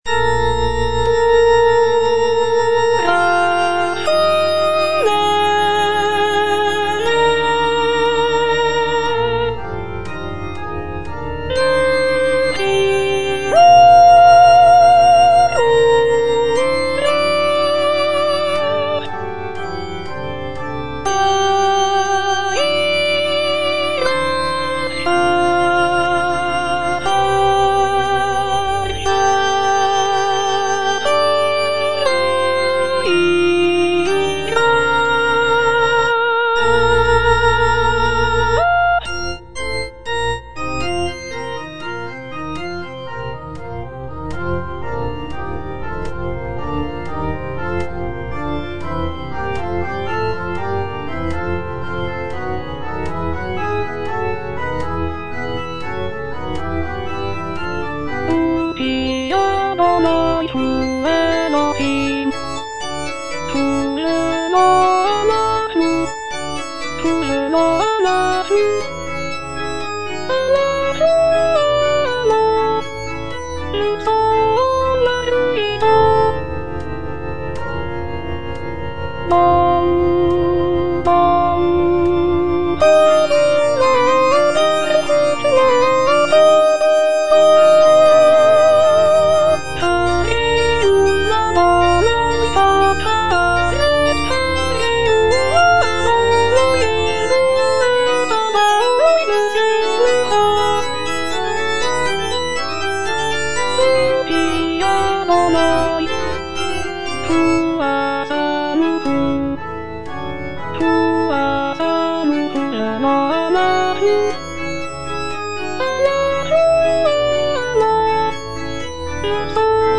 (alto II) (Voice with metronome) Ads stop
choral work